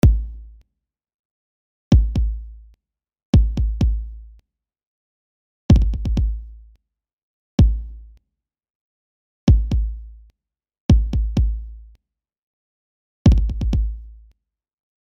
Kick (bei bestimmter EQ Setting) mit nachfolgenden Störgeräusch
Ich wollte einfach meine Kick "fetter machen" und habe dazu diverse Videos im Internet gefunden und selber rum experimentiert. Wenn ich folgende EQ Einstellung vornehme bekomme ich ein Störgeräusch direkt nachdem die Kick zu hören ist.